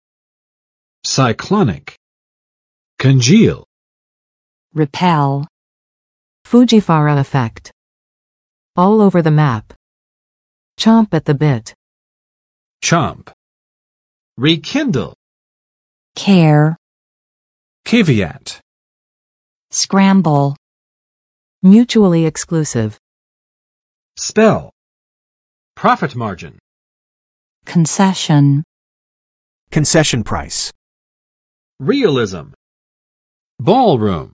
[saɪˋklɑnɪk] adj. 气旋的；飓风的